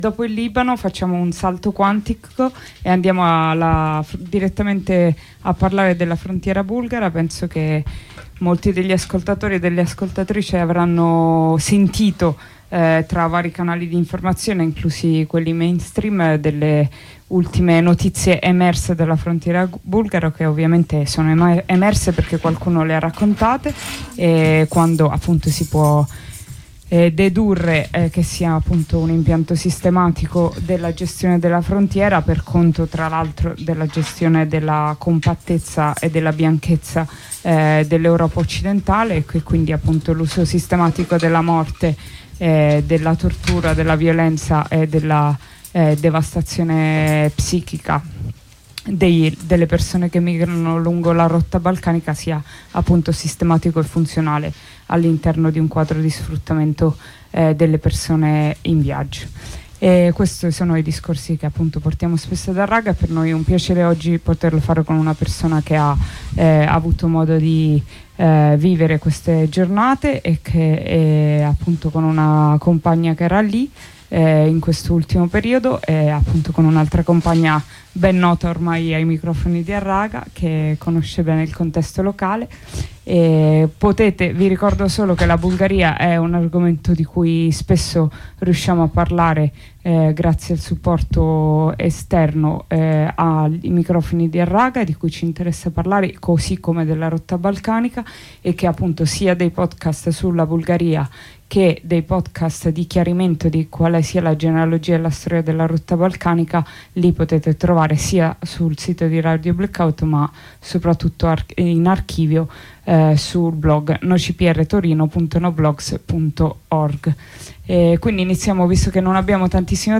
Ai microfoni di Harraga, in onda su Radio Blackout, con una compagna del collettivo Rotte Balcaniche abbiamo approfondito gli ultimi, tragici eventi accaduti al confine bulgaro-turco, di cui ha parlato anche la stampa nostrana.